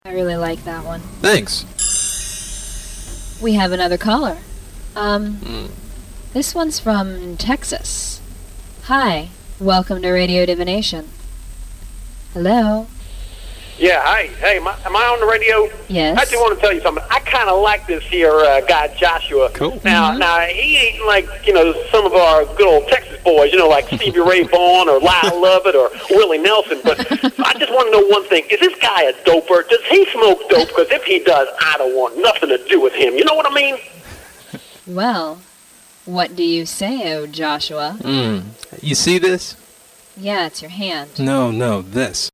All Instruments & Vocals
Turntablism & Scratches
Raps
Vocals